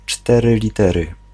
Ääntäminen
IPA: [kɔnt]